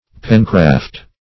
Pencraft \Pen"craft\, n.